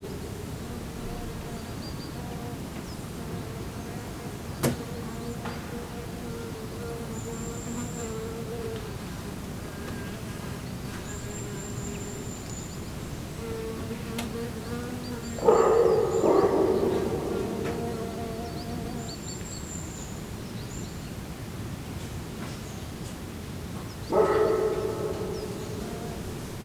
Directory Listing of /_MP3/allathangok/termeszetben/rovarok_premium/
balrarovar_jobbrakutya_octava_egerturistahaz00.26.WAV